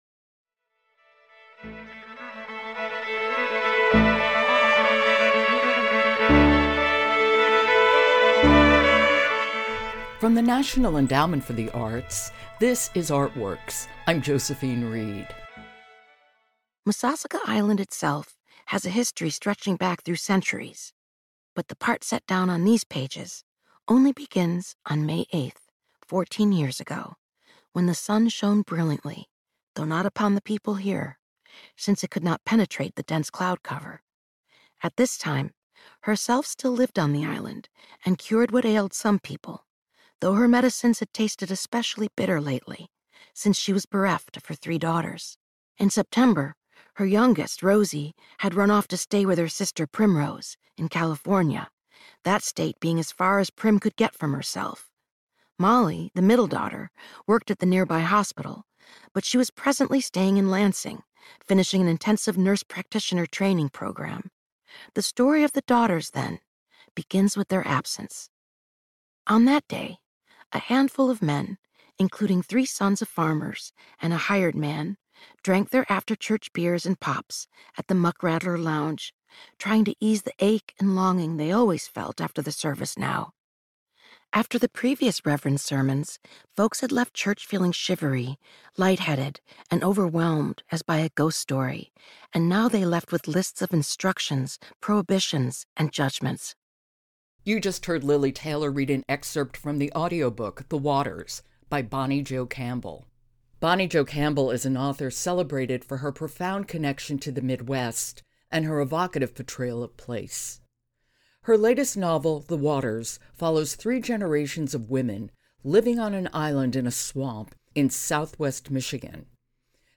Author Bonnie Jo Campbell discusses her novel, "The Waters," a tale set in the rich landscapes of rural Michigan that explores the intricate dynamics within a family of women in a shifting society.
“The Waters,” written by Bonnie Jo Campbell, narrated by Lili Taylor.